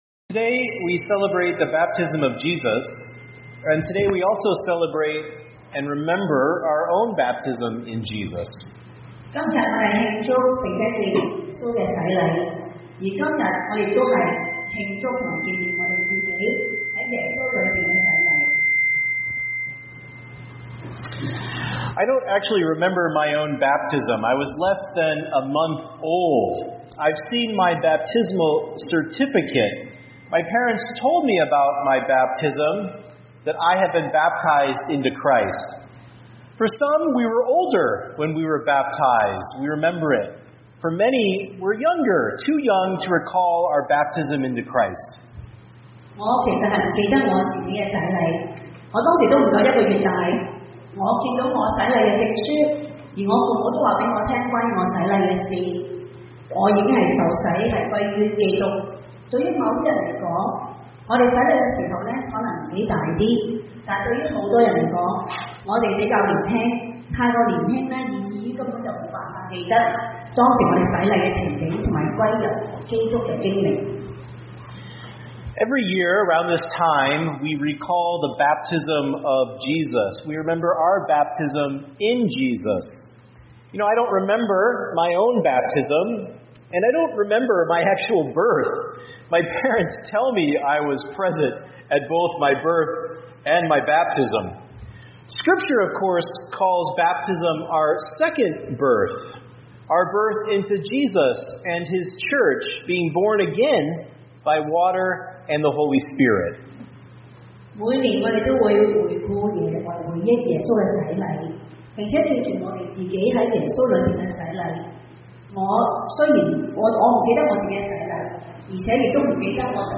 Chinese Service – Out of the Depths (Matthew 3:13-17)